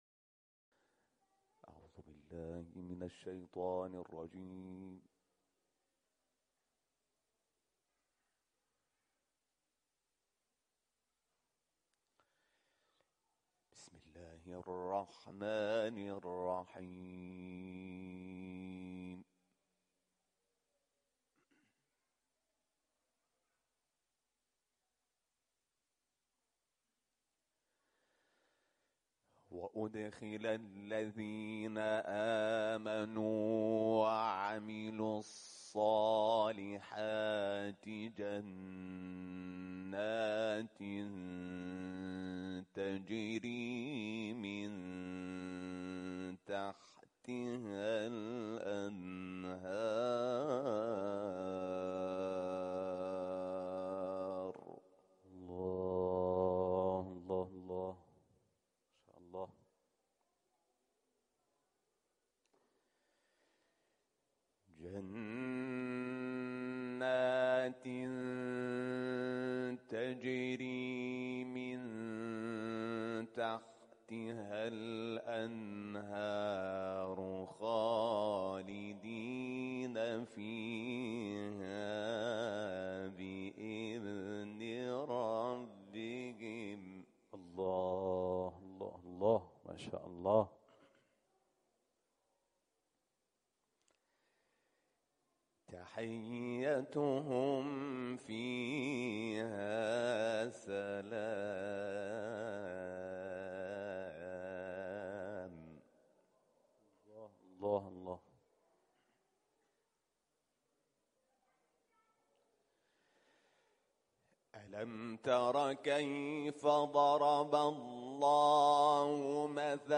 صوت | تلاوت مجلسی سوره «ابراهیم» با صدای حمید شاکرنژاد
تلاوت مجلسی حمید شاکرنژاد از آیات ۲۳ تا ۳۶ سوره ابراهیم و ۶ آیه نخست سوره بینه در محفل قرآنی مسجد ولایت اهواز تقدیم مخاطبان ایکنا می‌شود.